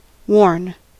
Ääntäminen
IPA : /woʊɹn/